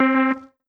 Synth Pluck (Closed On Sunday).wav